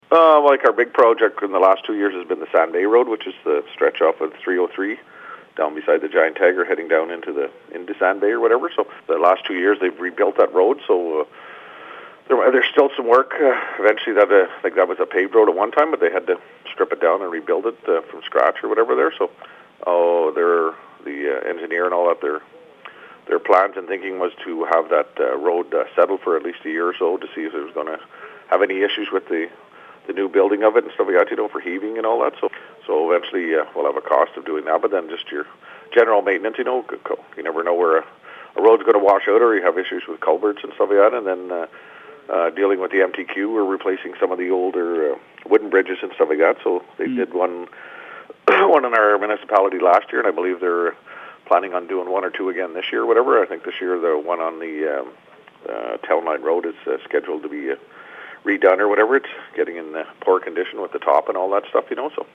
Clarendon Mayor Ed Walsh talk to CHIP 101.9 about the municipality’s budget for 2022, which passed in January.